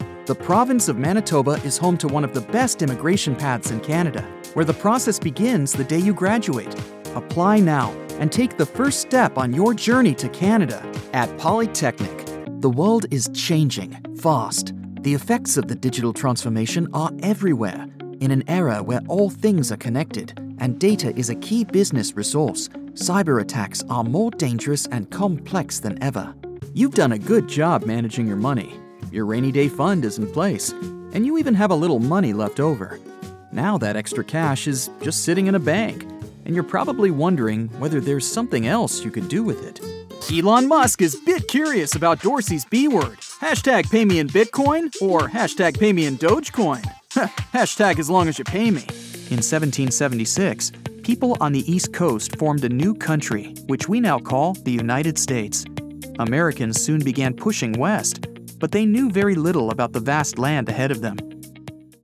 eLearning and Business / Presentation Demo Reel